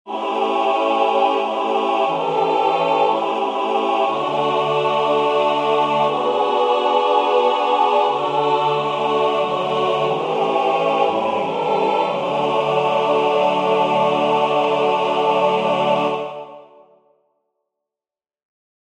Key written in: D Major
How many parts: 4
Type: Other male
All Parts mix: